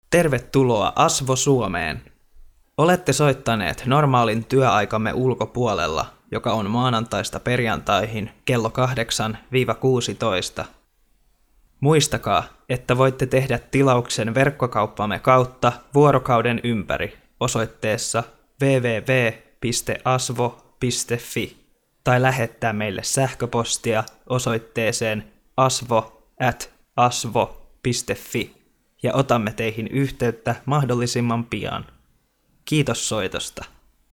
ASWO Finsk telefonsvarer